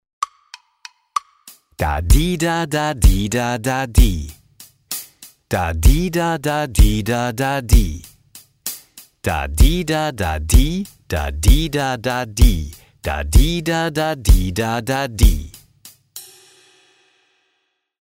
Im folgenden Hörbeispiel habe ich den Blindtext mit einem Beat unterlegt, der die Betonungen noch deutlicher macht als ein einfaches Metronom: